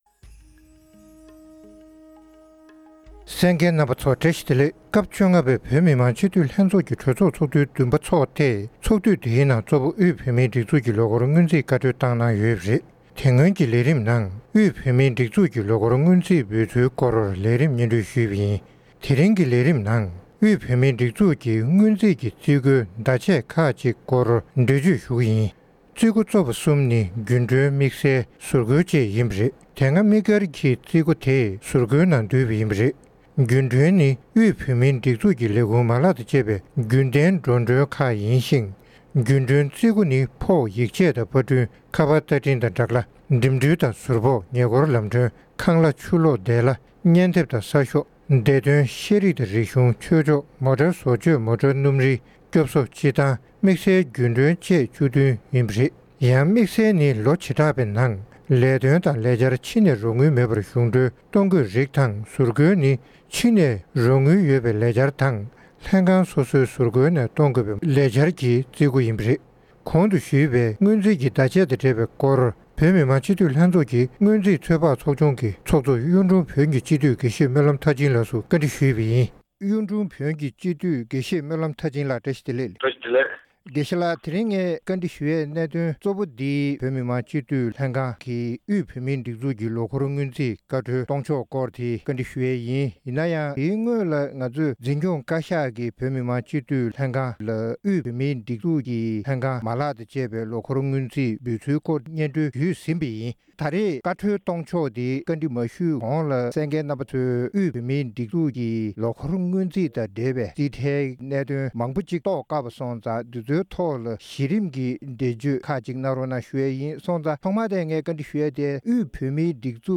༄༅།    །ཐེངས་འདིའི་དབུས་བོད་མིའི་སྒྲིག་འཛུགས་ཞེས་པའི་ལེ་ཚན་ནང་།    དབུས་བོད་མིའི་སྒྲིག་འཛུགས་ཀྱི་ལོ་འཁོར་སྔོན་རྩིས་དང་འབྲེལ་བའི་རྩིས་འགོའི་བརྡ་ཆད་ཁག་ཅིག་སྐོར་སྔོན་རྩིས་ཚོད་དཔག་ཚོགས་ཆུང་གི་ཚོགས་གཙོ་དགེ་བཤེས་སྨོན་ལམ་མཐར་ཕྱིན་ལགས་སུ་བཀའ་འདྲི་ཞུས་ཏེ་ཕྱོགས་སྒྲིག་ཞུས་པ་ཞིག་གསན་རོགས་གནང་།།